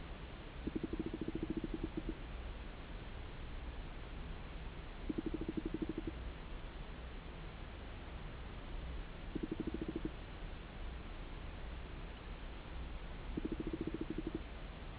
In the course of our observations we have found that the planktonic prey are surprisingly noisy.  The following raw audio recording is 15 seconds long, and was obtained while a copepod was apparently resting on the microphone:
The behavior continues for bouts as long as 15 minutes.